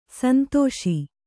♪ santōṣi